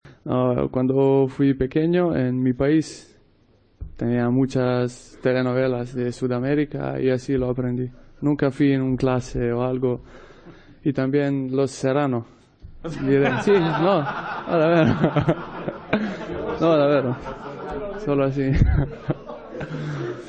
El nuevo fichaje del Sevilla expicó en rueda de prensa por qué habla tan bien español: "Cuando fui pequeño en mi país teníamos muchas telenovelas. Nunca di clase. También con Los Serrano"